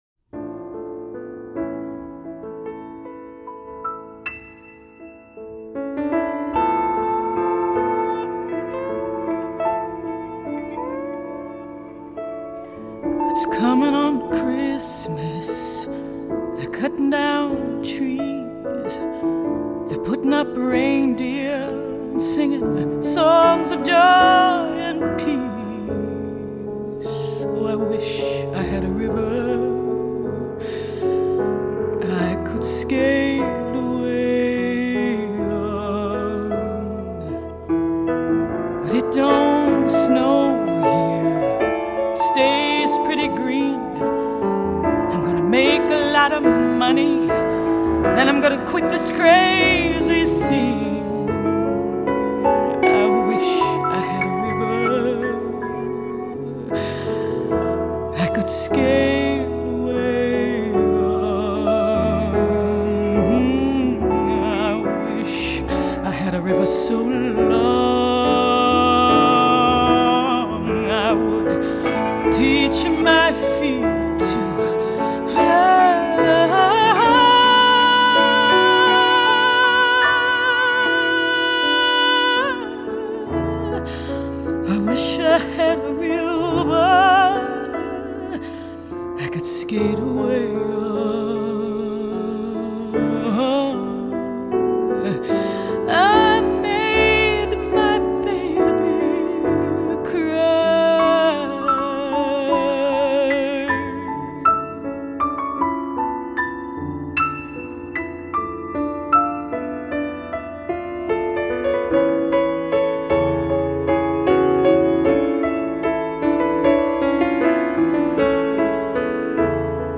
the world-famous opera singer
jazz pianist
guitarist